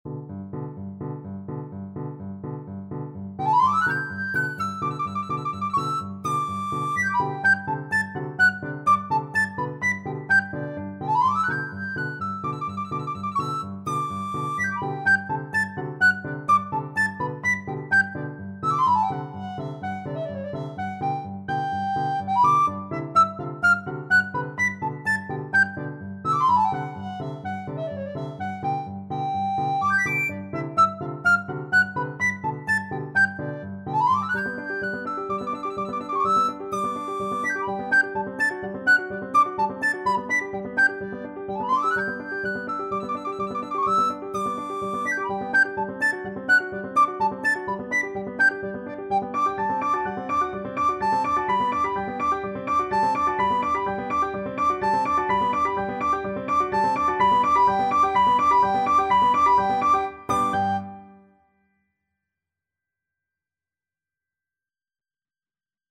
Classical Tchaikovsky, Pyotr Ilyich Chinese Dance from Nutcracker Suite, Op. 71a Soprano (Descant) Recorder version
4/4 (View more 4/4 Music)
G major (Sounding Pitch) (View more G major Music for Recorder )
Allegro moderato (=126) (View more music marked Allegro)
Classical (View more Classical Recorder Music)
chinese_dance_tchaikovsky_REC.mp3